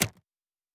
pgs/Assets/Audio/Sci-Fi Sounds/Interface/Click 9.wav
Click 9.wav